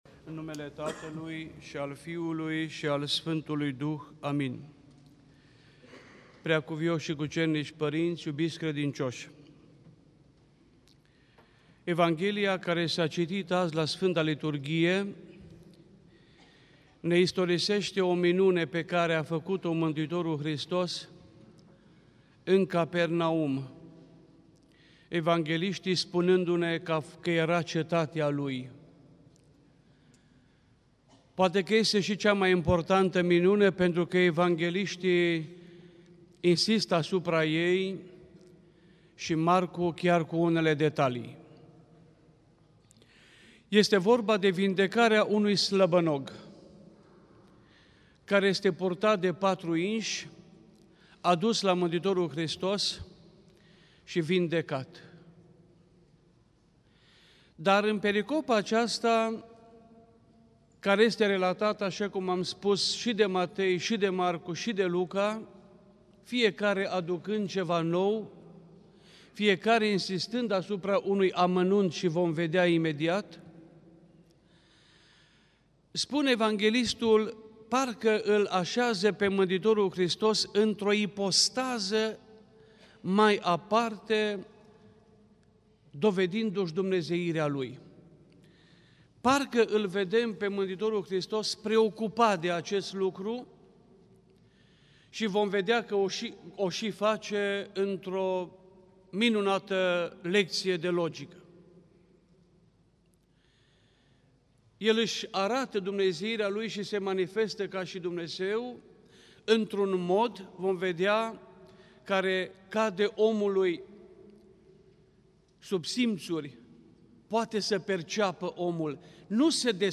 Predică la Duminica a 6-a după Rusalii
Cuvânt de învățătură
rostit în Duminica a 6-a după Rusalii (Vindecarea slăbănogului din Capernaum), la Catedrala